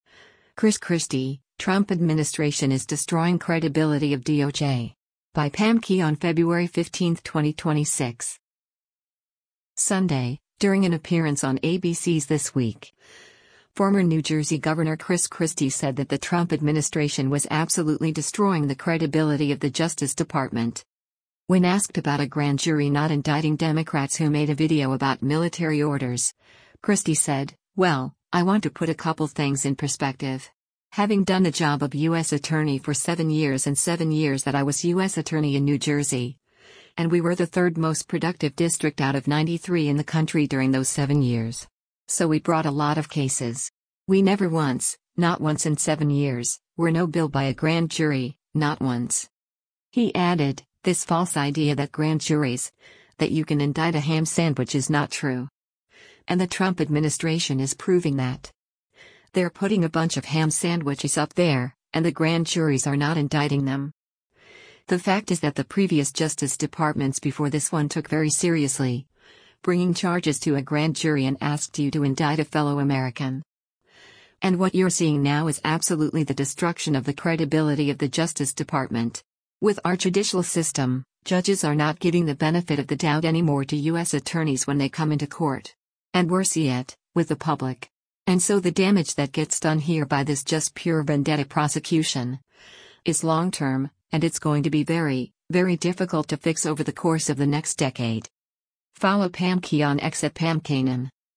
Sunday, during an appearance on ABC’s “This Week,” former New Jersey Gov. Chris Christie said that the Trump administration was absolutely destroying the credibility of the Justice Department.